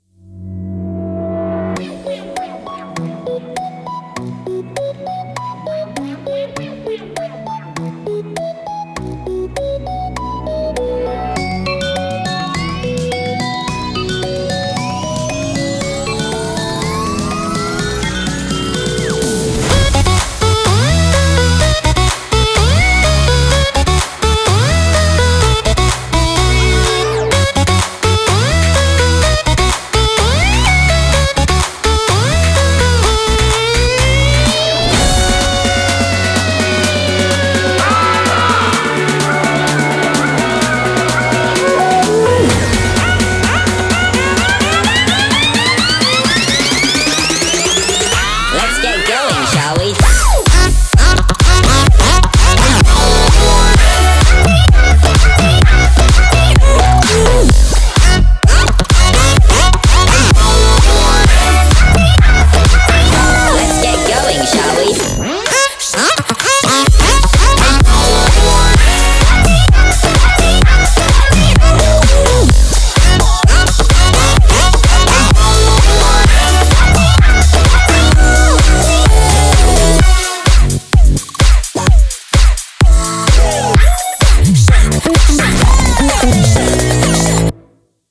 BPM100-128